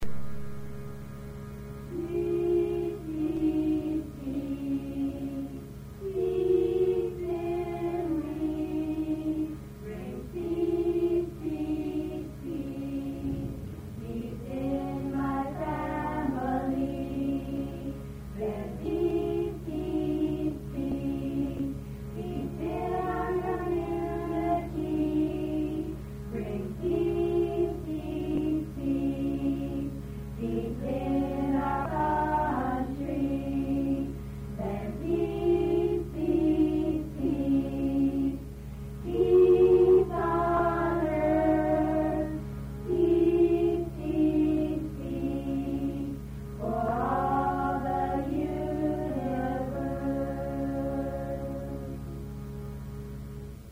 1. Devotional Songs
Major (Shankarabharanam / Bilawal)
Simple
Medium Slow